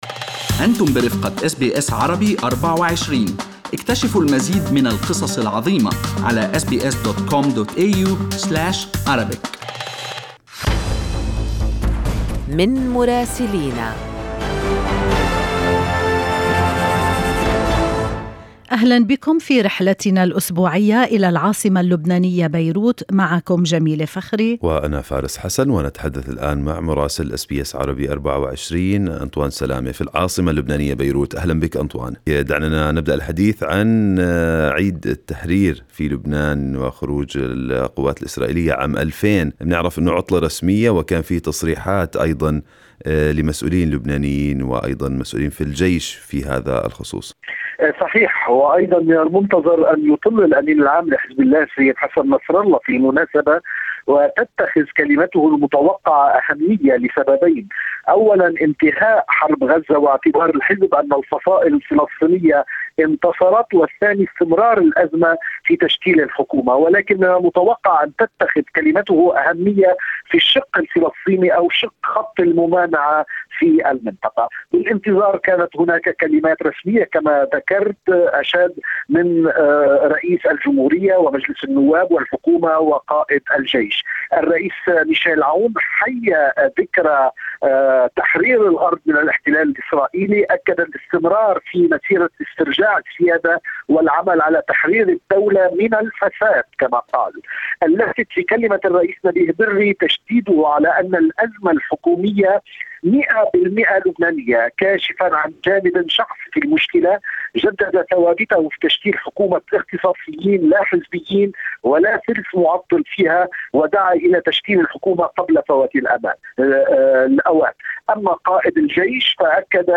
يمكنكم الاستماع إلى تقرير مراسلنا في بيروت بالضغط على التسجيل الصوتي أعلاه.